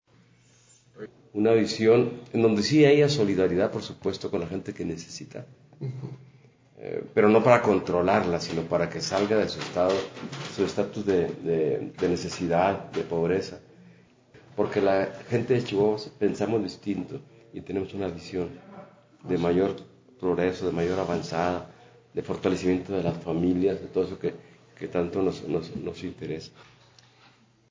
MENSAJE DE MARIO VÁZQUEZ